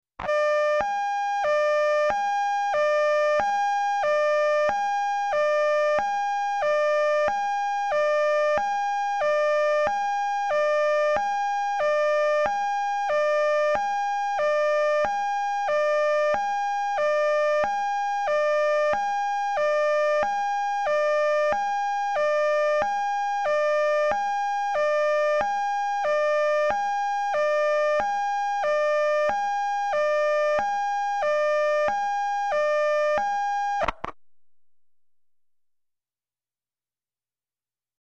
High / Low Siren; Close Perspective 1.